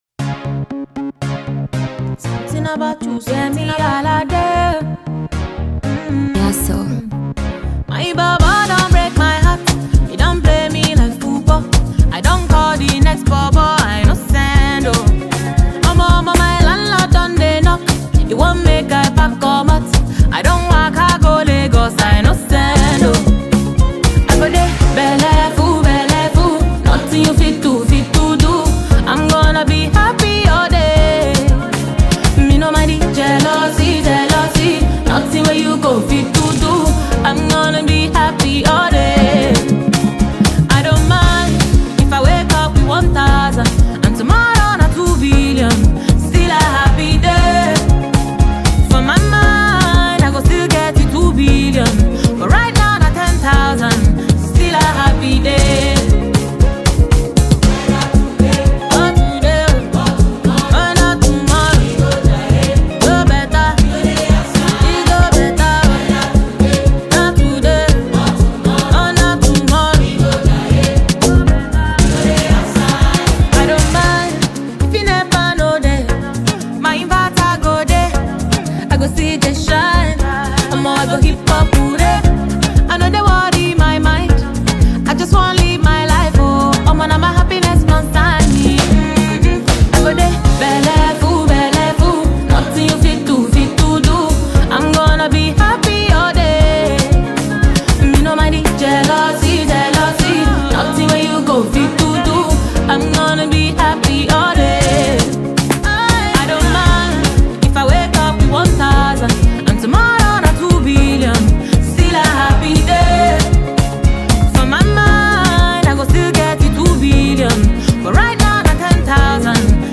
a celebrated Nigerian female singer